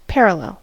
parallel: Wikimedia Commons US English Pronunciations
En-us-parallel.WAV